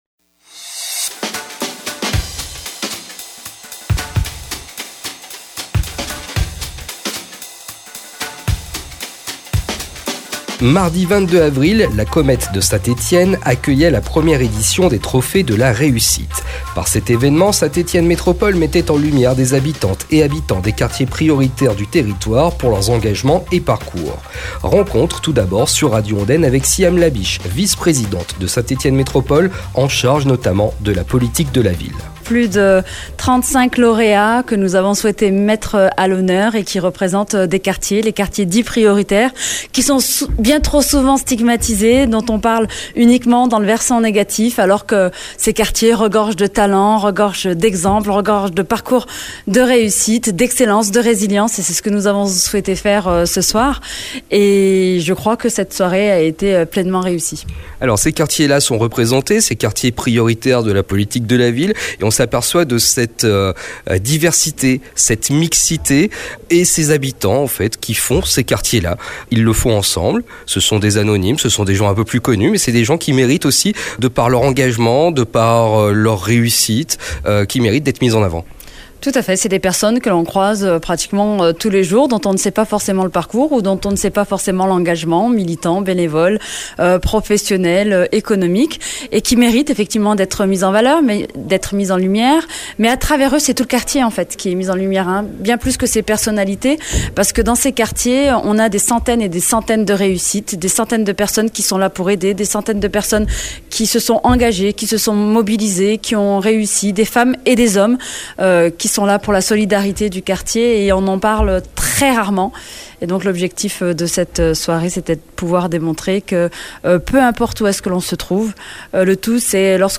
Siham Labich, vice présidente de Saint-Etienne Métropole en charge de la politique de la ville